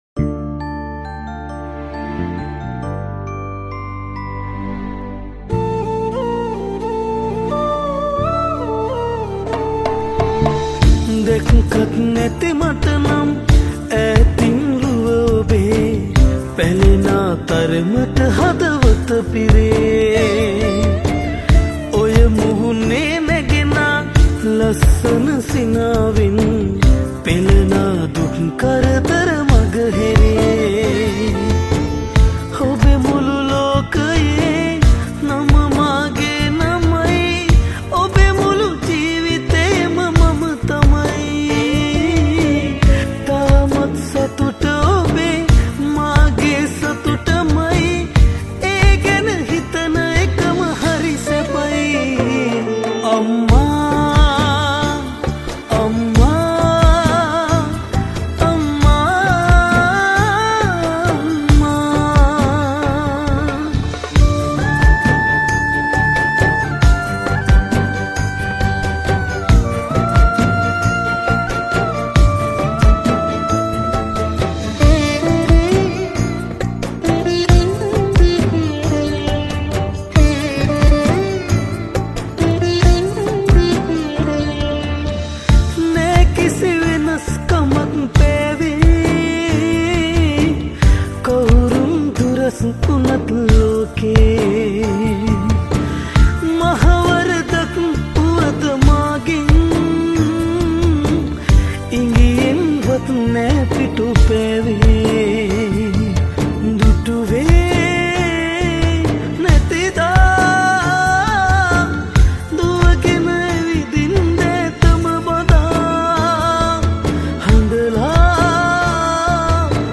Live Percussions